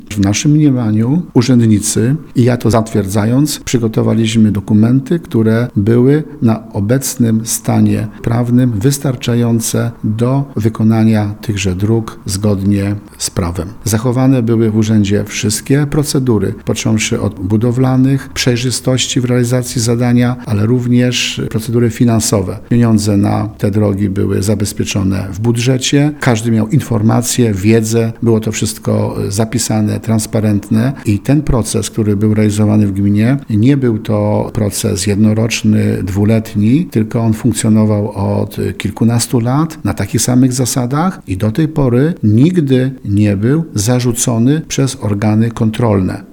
Czuję się niewinny pod względem etycznym i prawnym. Gmina jest po to, by budować drogi mieszkańcom – tak wójt gminy Tarnów Grzegorz Kozioł komentuje dla Radia RDN Małopolska zarzuty prokuratorskie o przekroczenie uprawnień i działanie na szkodę interesu publicznego.